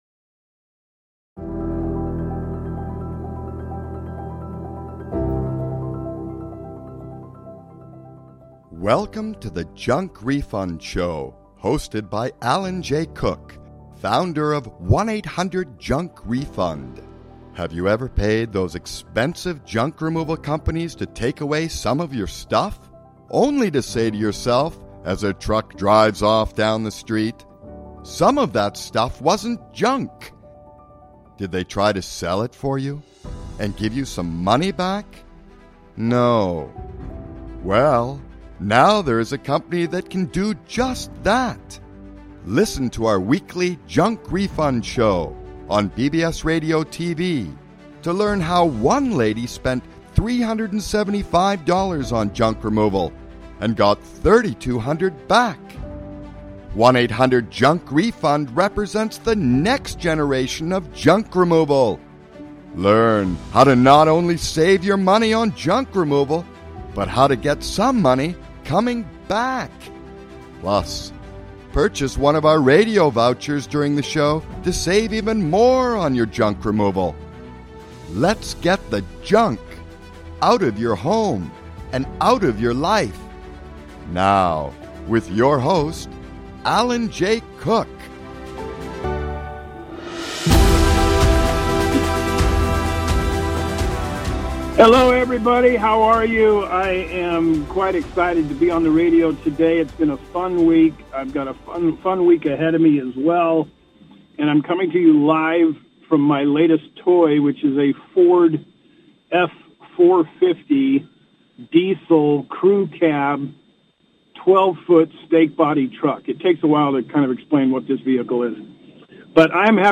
Live from inside a Ford Enterprise Diesel Rental Truck, from Bethesda, Maryland.